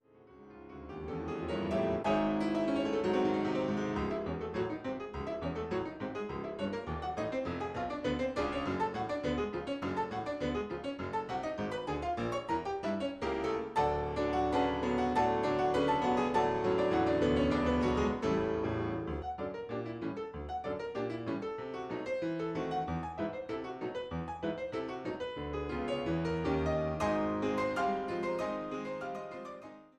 Piano
Prestissimo